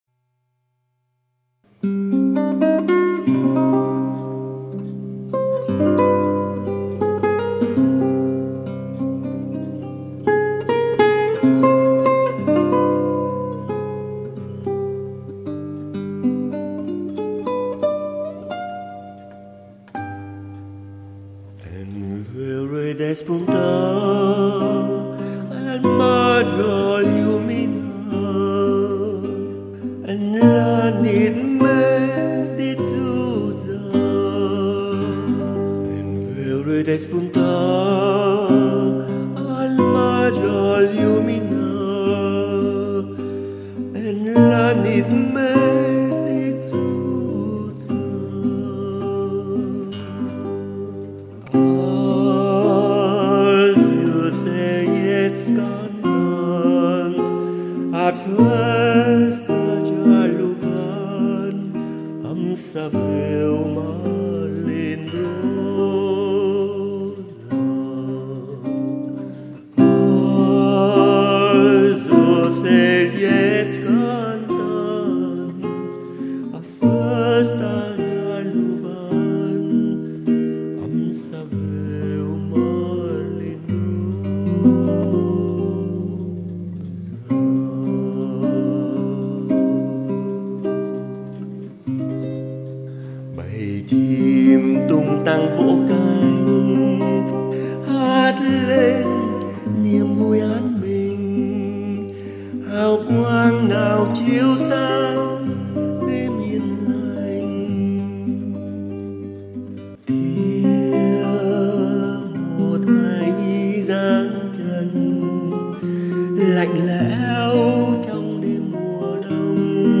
« Bài hát của loài chim » là một dân ca quen thuộc nhất trong văn hoá Catalan, và mặc dù đây là một bài hát mùa Noël, nhưng người Catalan hát nó trong nhiều dịp khác nhau, như một thông điệp sống chung hoà b́nh giữa các dân tộc.
Xin được giới thiệu đến các bạn Việt Nam tác phẩm nổi tiếng này, qua lời Việt tôi viết ra theo tinh thần của bài hát nguyên thuỷ, gồm hơn mười phiên khúc khác nhau, ở đây chỉ hát phiên khúc đầu tiên.